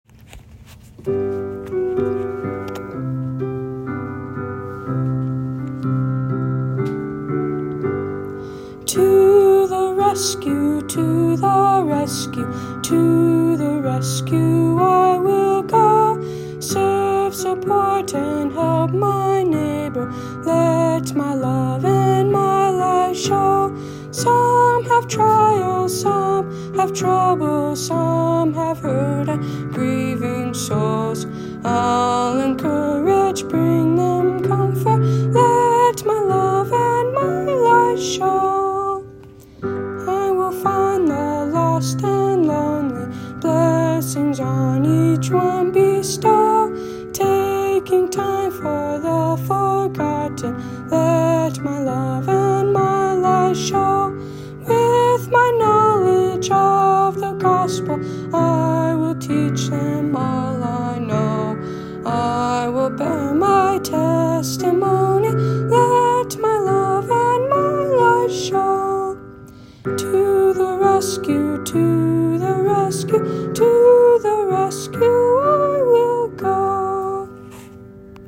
Voicing/Instrumentation: Primary Children/Primary Solo